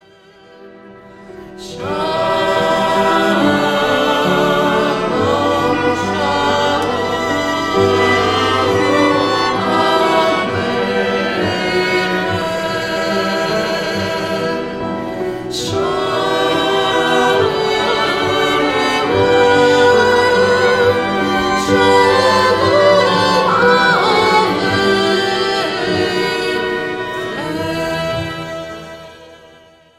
A selection of beautifully arranged music for Shabbat